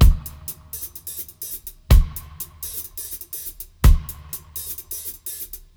121-FX-01.wav